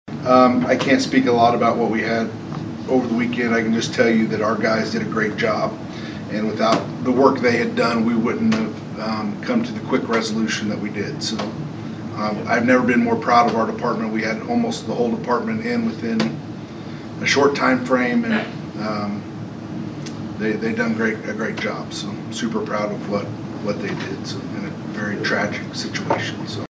Later in the meeting, Police Chief Jeff Ray says he was very proud of his department’s work.